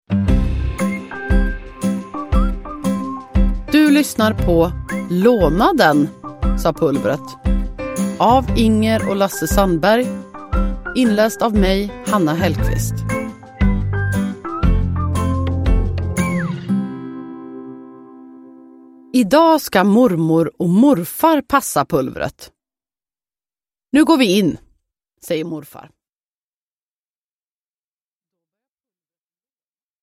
Låna den, sa Pulvret – Ljudbok – Laddas ner
Lyssna på Inger och Lasse Sandbergs klassiska böcker om Pulvret inlästa av Hanna Hellquist.
Uppläsare: Hanna Hellquist